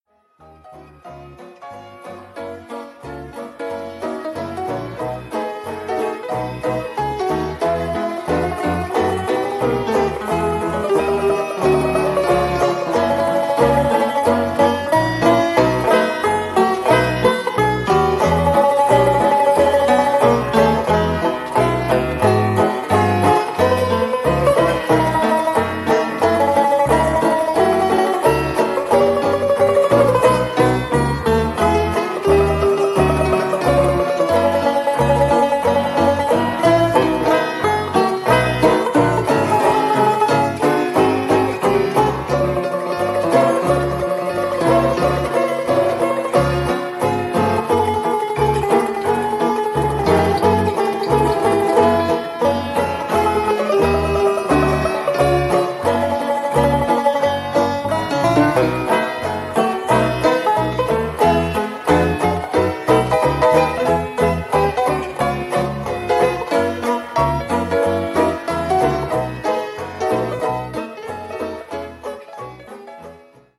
tenor banjo
piano